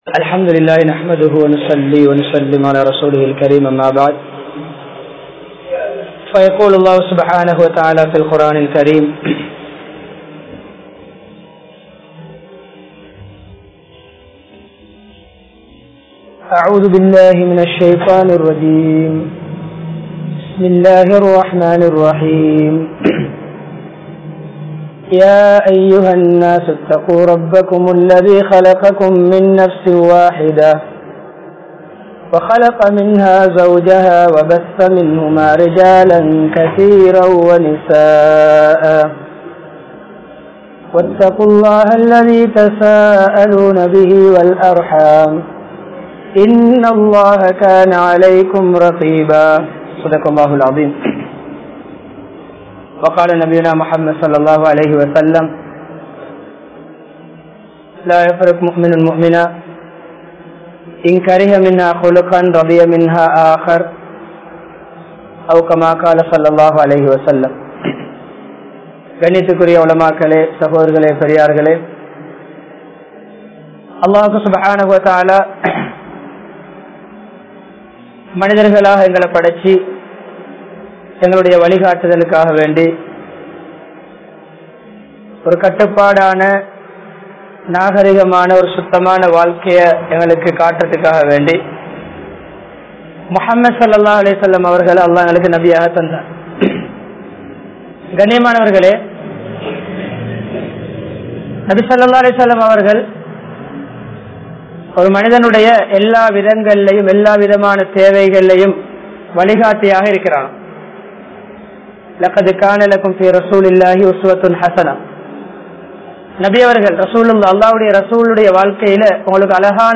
Natp Niraintha Kudumba Vaalkai (நட்பு நிறைந்த குடும்ப வாழ்க்கை) | Audio Bayans | All Ceylon Muslim Youth Community | Addalaichenai